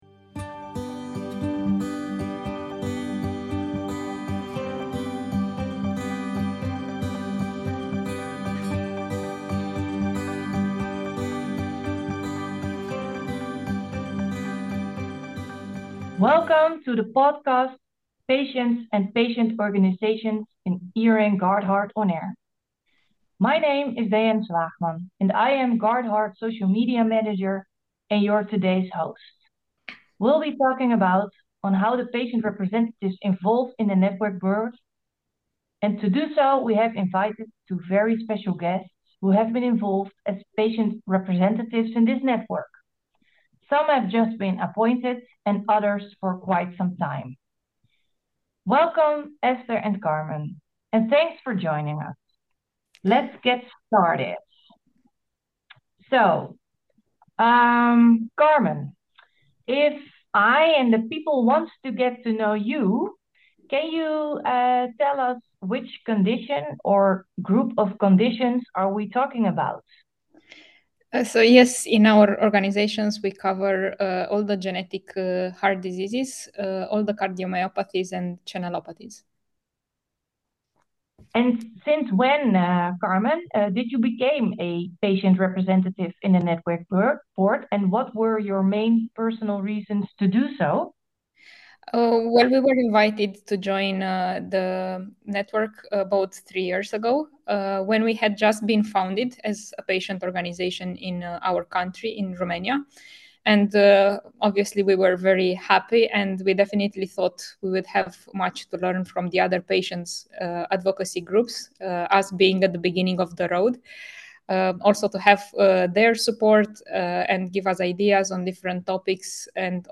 In March 2024 ERN GUARDHEART started with a podcast series in which all ePags or patient representatives are requested to participate. The idea is that in each recording, we are interviewing two representatives as a duo.